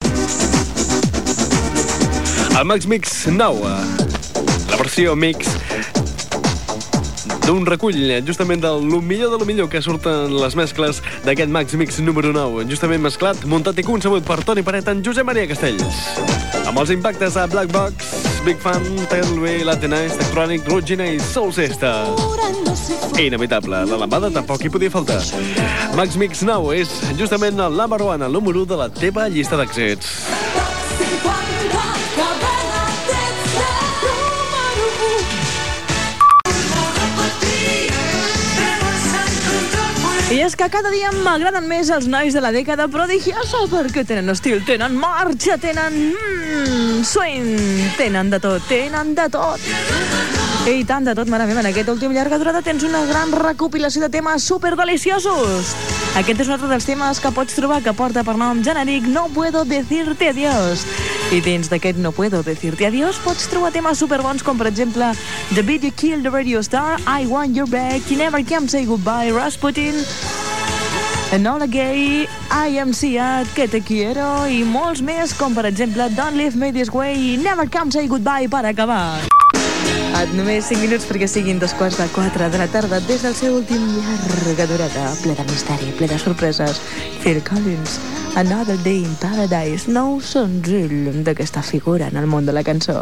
Presentació del Max Mix 9, indicatiu del programa, presentació d'un tema musical.
Musical
FM